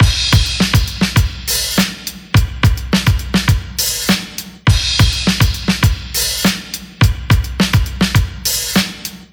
• 103 Bpm Breakbeat G# Key.wav
Free drum groove - kick tuned to the G# note. Loudest frequency: 2413Hz
103-bpm-breakbeat-g-sharp-key-YiH.wav